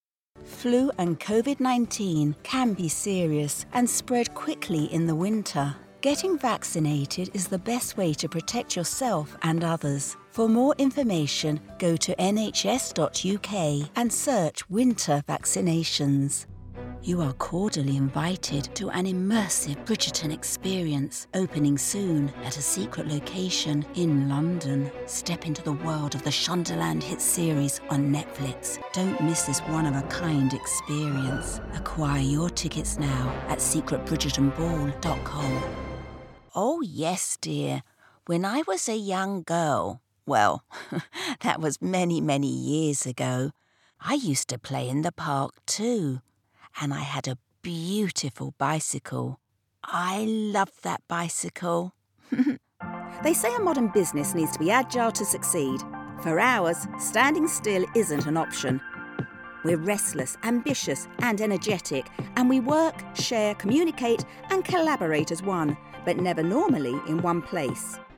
Englisch (Britisch)
Warm
Freundlich